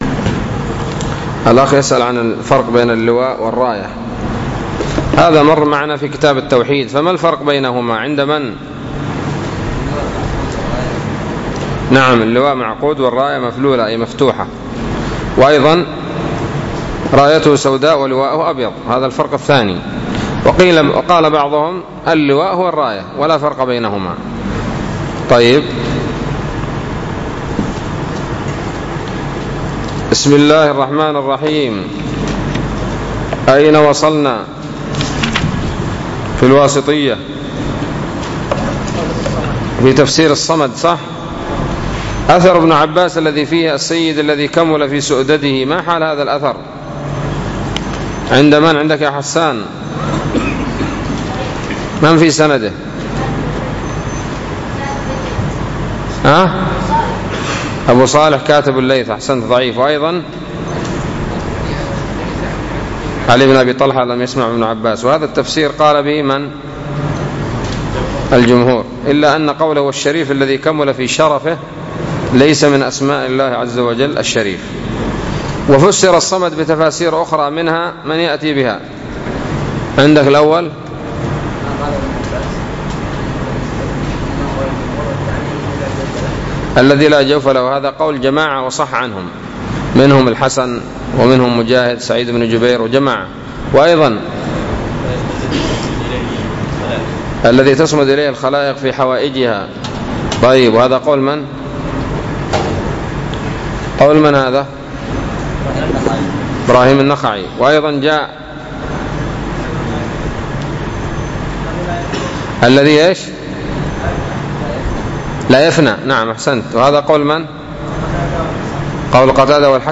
الدرس الخامس والثلاثون من شرح العقيدة الواسطية